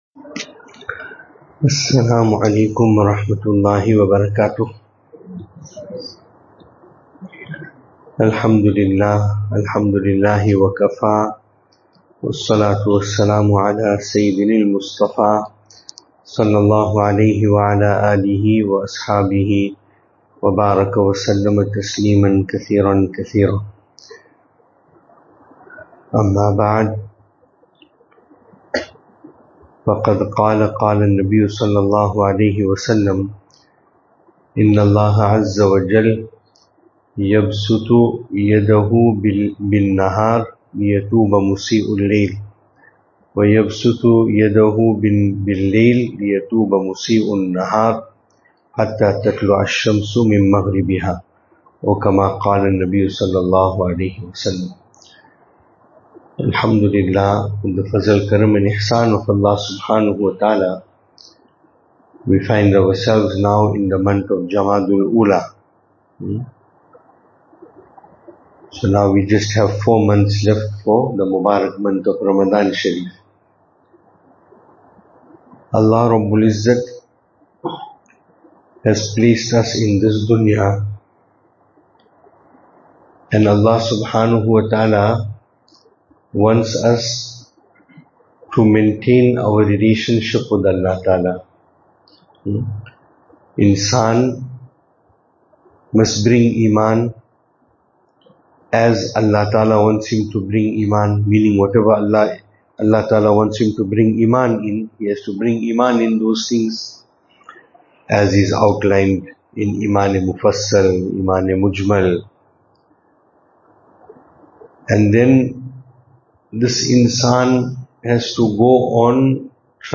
2025-10-24 Establish a relationship of love with Allah Ta’ala Venue: Albert Falls , Madressa Isha'atul Haq Series: JUMUAH Service Type: Jumu'ah « Programme from Musjid Rabia, Estcourt Akhlaaq of our Akaabireen.